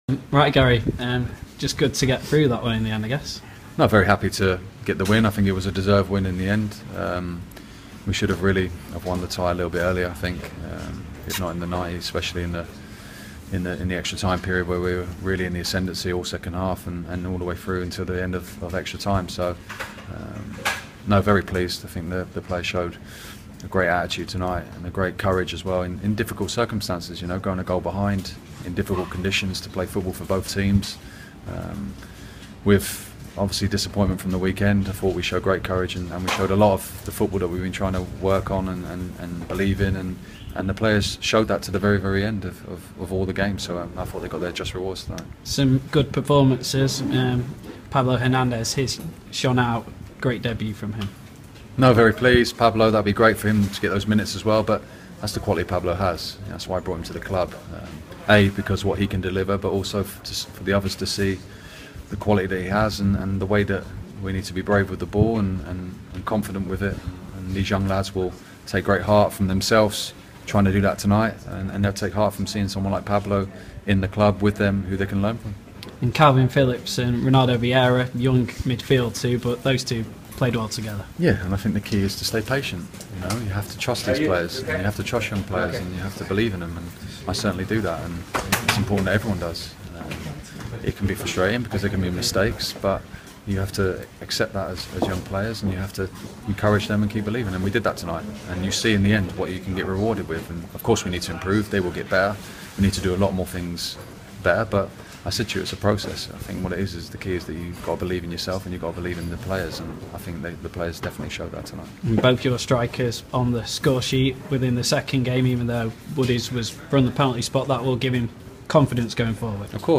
Leeds United boss Garry Monk speaks after Whites defeat Fleetwood on penalties to progress to EFL Cup Second Round